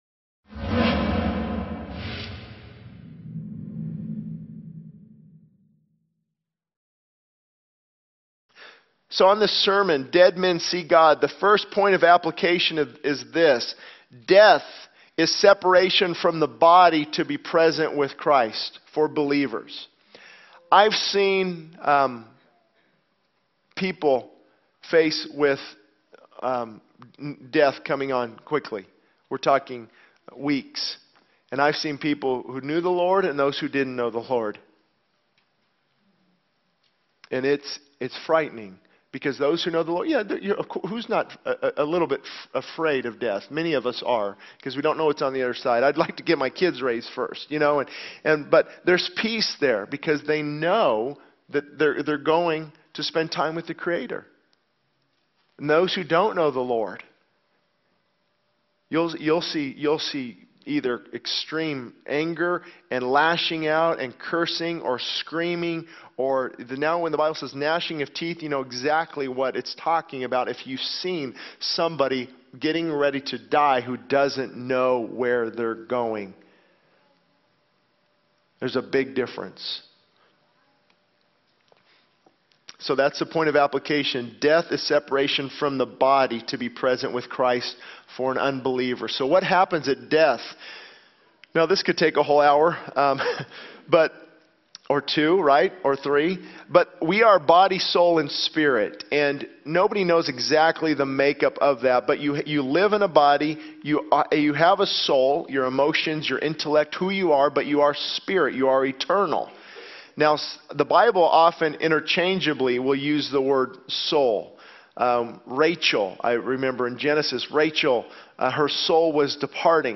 This sermon discusses the concept of death as separation from the body to be present with Christ for believers, highlighting the contrasting reactions of those who know the Lord and those who do not. It delves into the understanding of what happens at death, emphasizing the eternal nature of the spirit and the resurrection of the body. The sermon also explores the idea of being prepared for Christ's return and the importance of being dead to the world to draw closer to God.